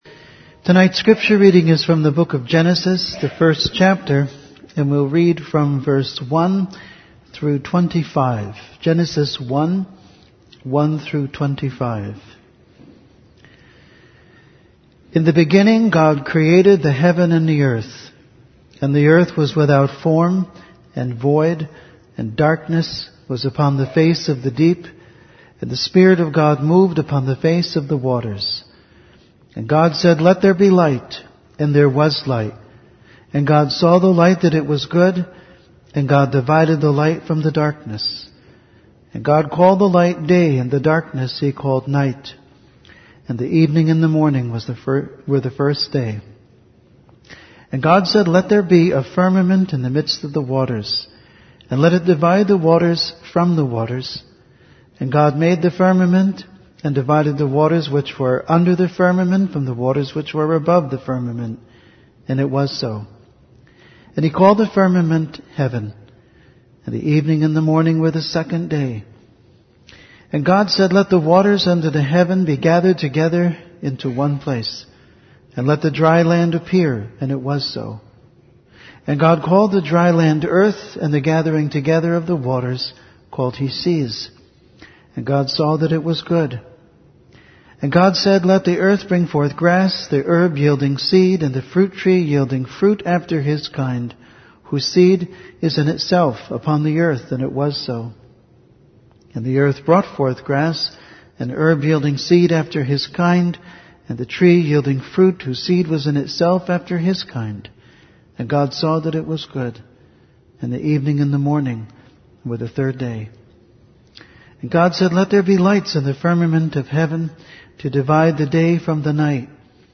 Sermon Date: 6/16/2016 (THU) Scripture Reference: Genesis 1:1 1. The idea of creation 2. The unfolding of creation 3. The purpose of creation